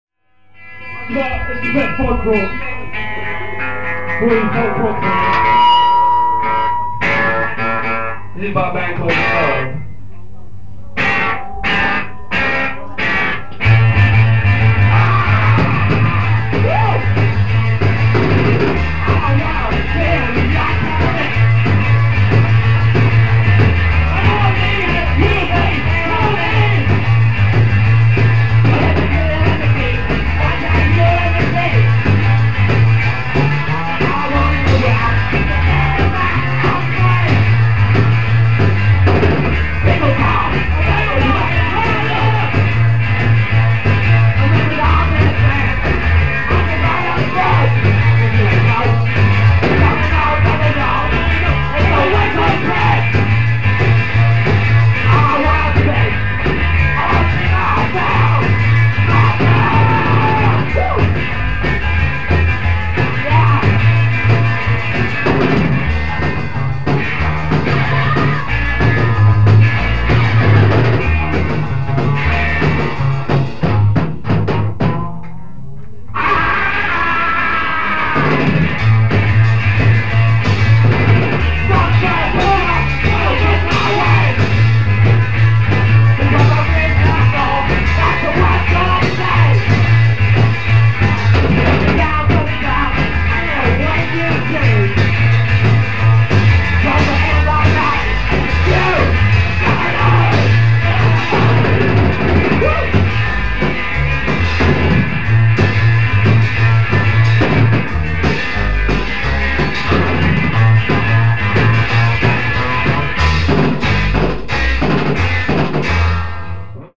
Extra bonus track from same gig: